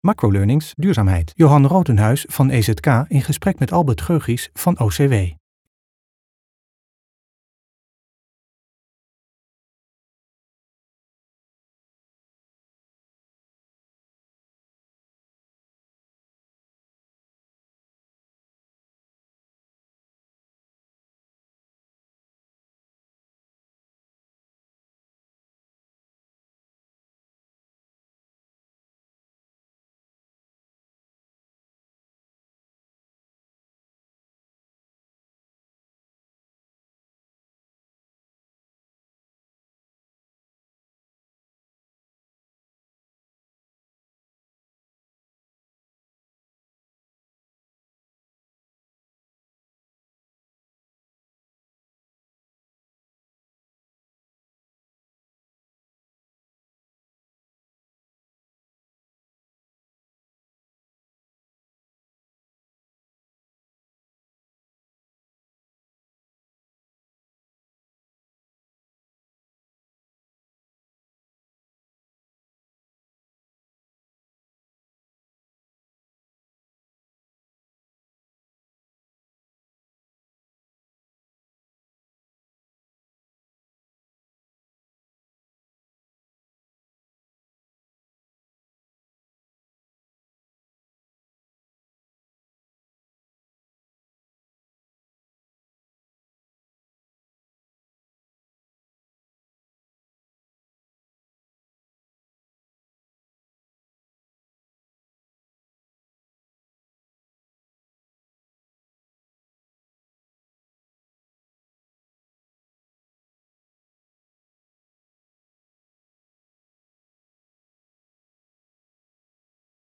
(Intro met abstracte beelden met daaronder een korte begintune.)
(Outro met abstracte beelden en korte eindtune.)